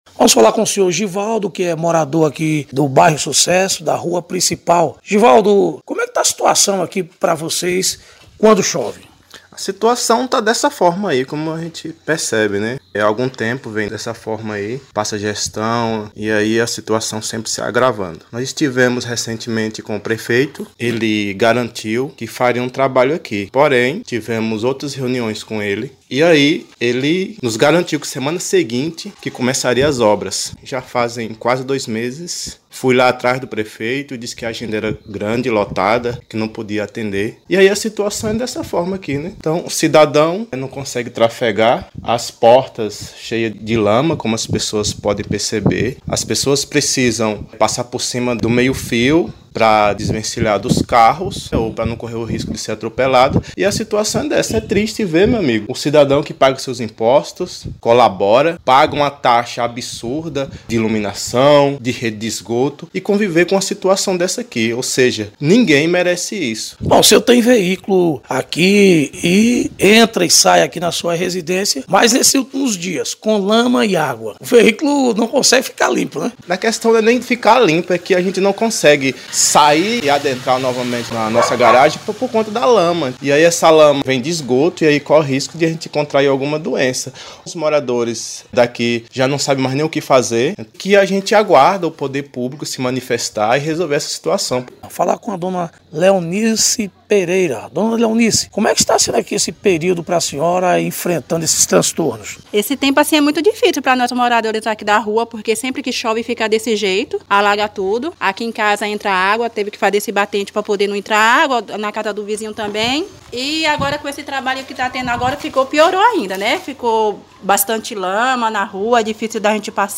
Reportagem: moradores do bairro sucesso – reclamação sobre lama e muita água nas ruas